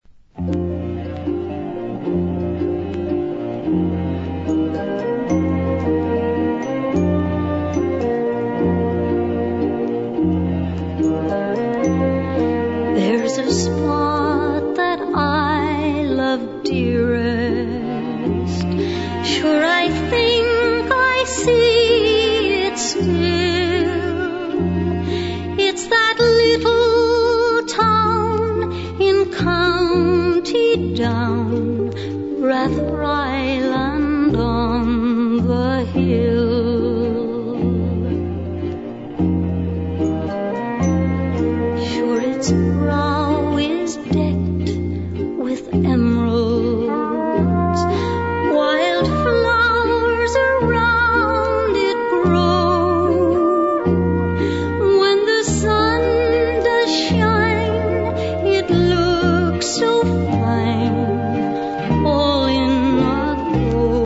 (vinyl)